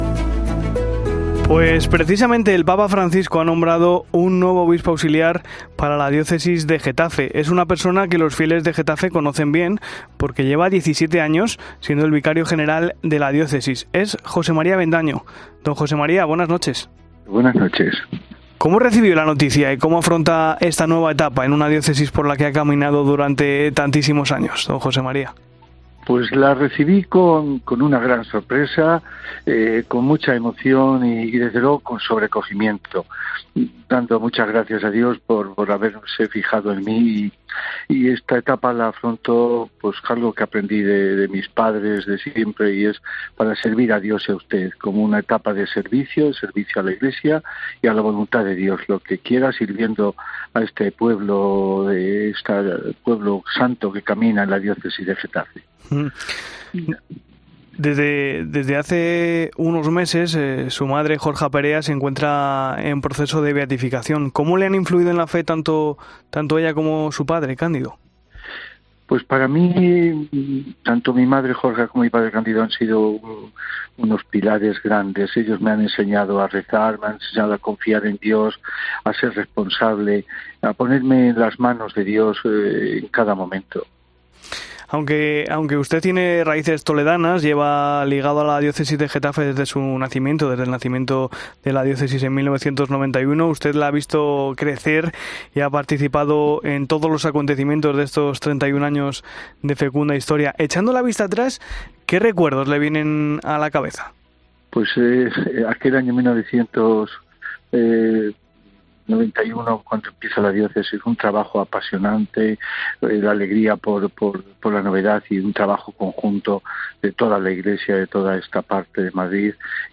El obispo auxiliar electo de Getafe repasa en 'La Linterna de la Iglesia' su trayectoria vital y al servicio de la Iglesia que peregrina en el sur de Madrid